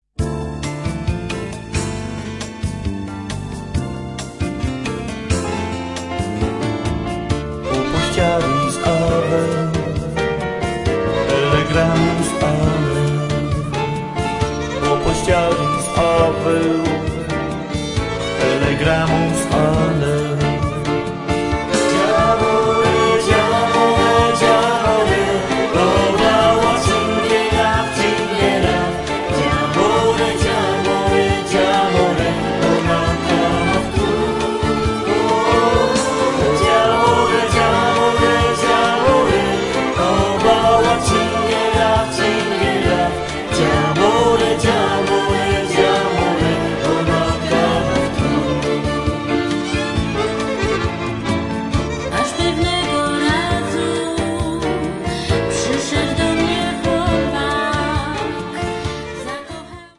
Organ
Violin
Accordion
Backup Singers